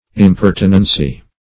Search Result for " impertinency" : The Collaborative International Dictionary of English v.0.48: Impertinency \Im*per"ti*nen*cy\, n. Impertinence.